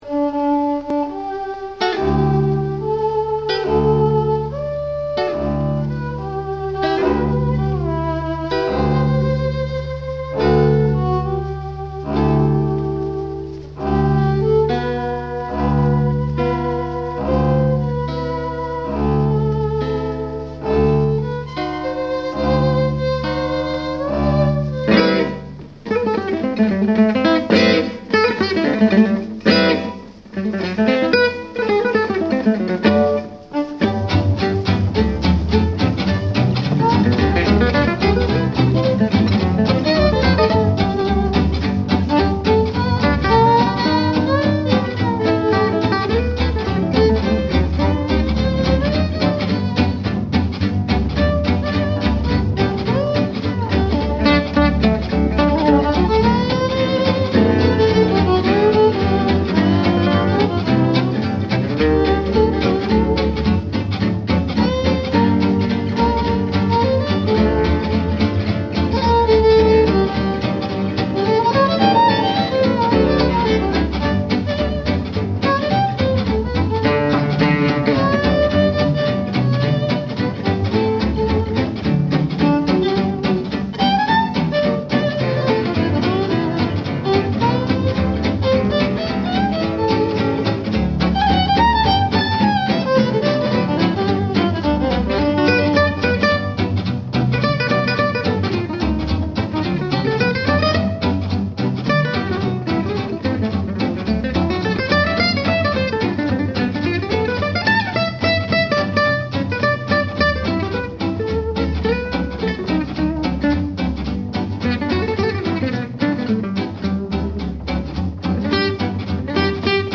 Version jazz, partie d’une improvisation - 1946